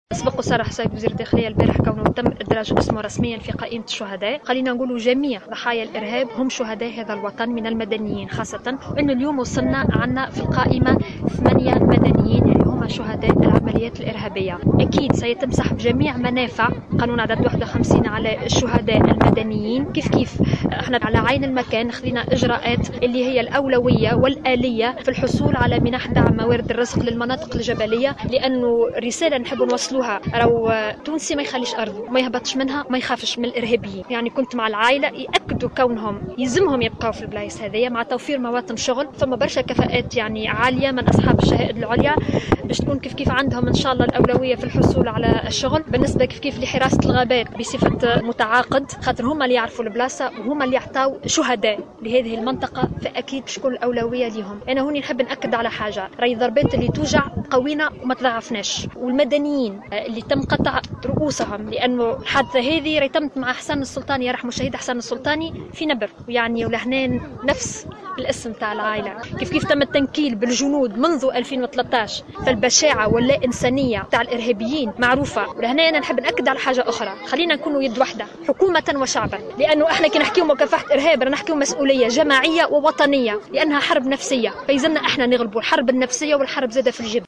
وقد حضر الجنازة إلى جانب عائلة الفقيد وأهالي منطقة أولاد سلاطنية كاتبة الدولة المكلفة بملف شهداء وجرحى الثورة ماجدولين الشارني ووالي سيدي بوزيد، مراد محجوبي، وعدد من نشطاء المجتمع المدني وعدد من المواطنين.